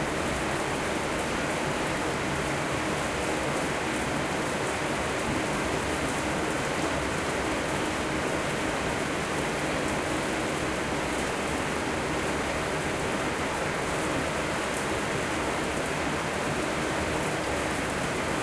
showerMuffledLoop.ogg